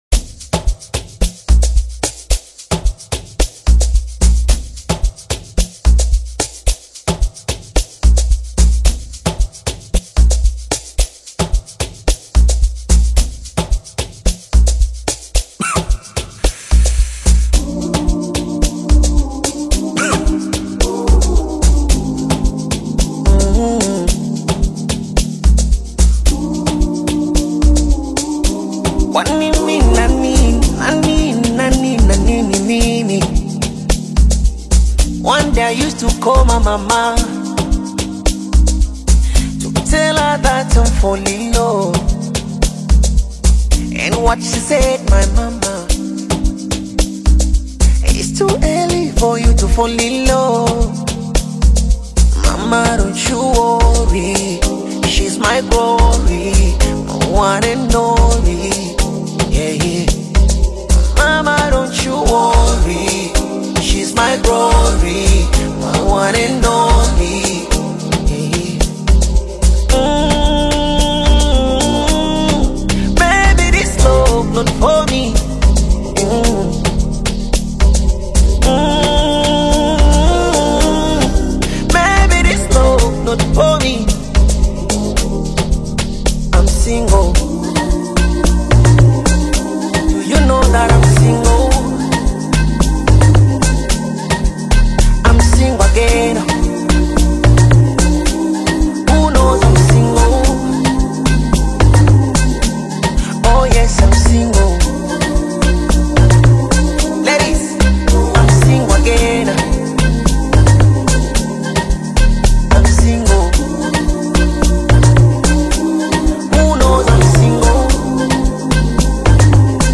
a fusion of Bongo Fleva and Amapiano